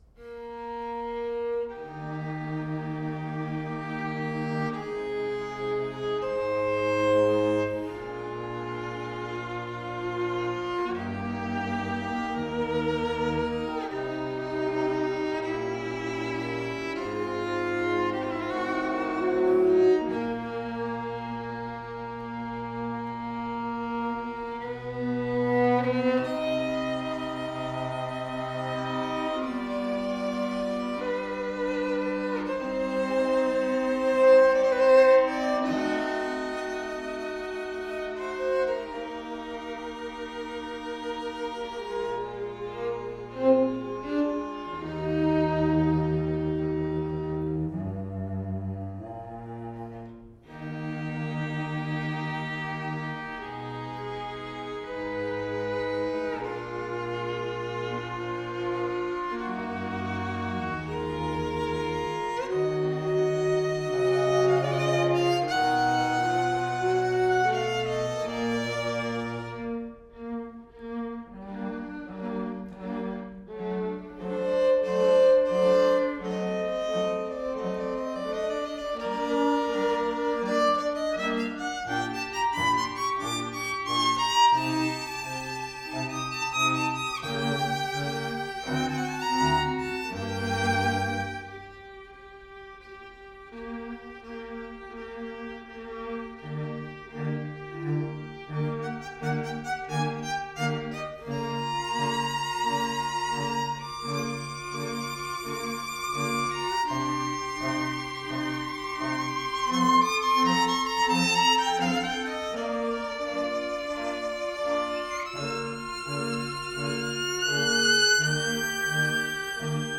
Soundbite 2nd Movt